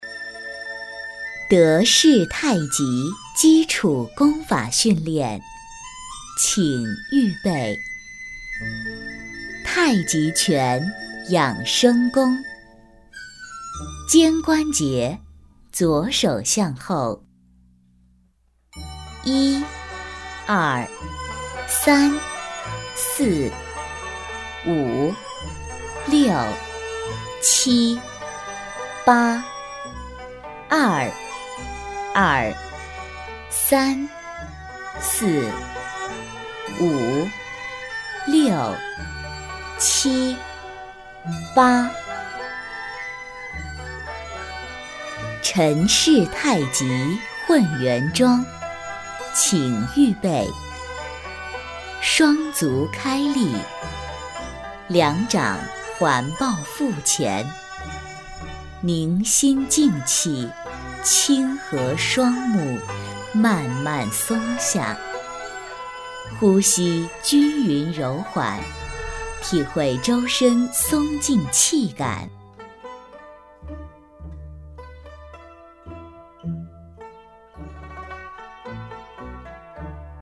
女130号-说明介绍配音-自然-视频配音德丰太极